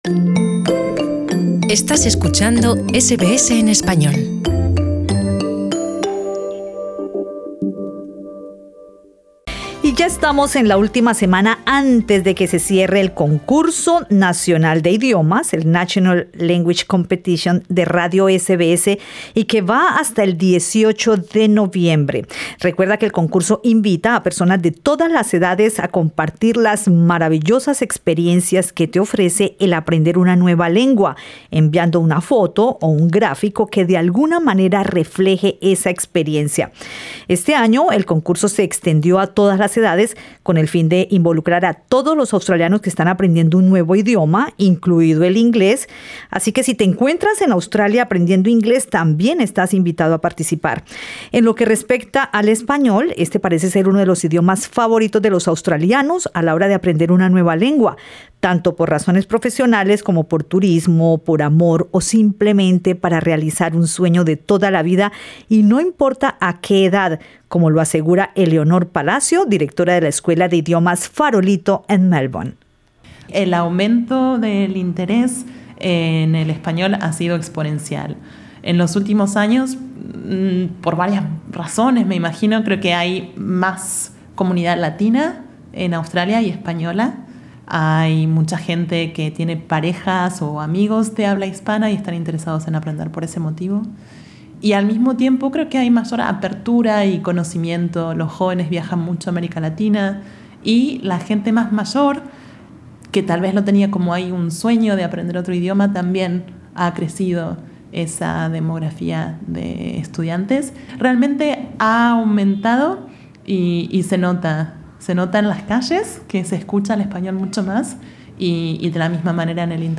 Profesores de español de varias instituciones de Melbourne narraron a Radio SBS experiencias interesantes de su práctica de enseñar el idioma a niños y adultos en Australia